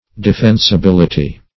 Defensibility \De*fen`si*bil"i*ty\, n.